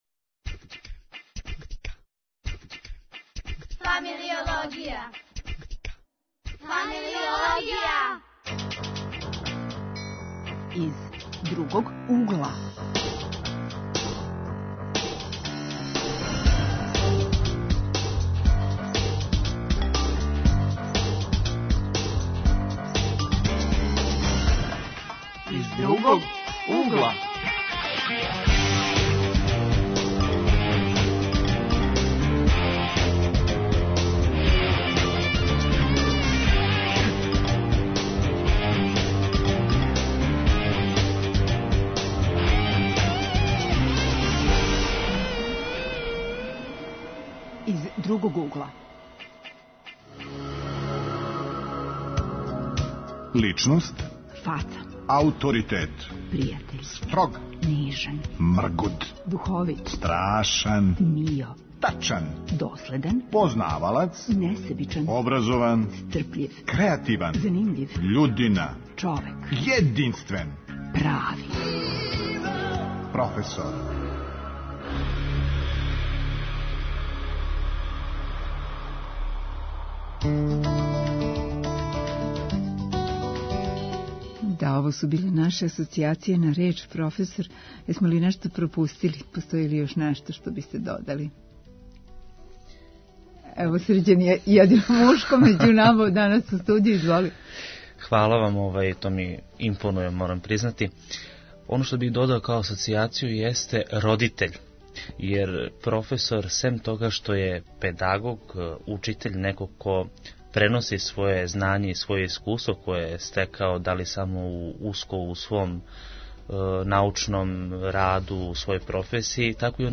Гости у студију су студенти и професор који инспирише, добитник награде Најбољи едукатор.